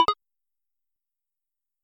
SFX_UI_OpenMenu.mp3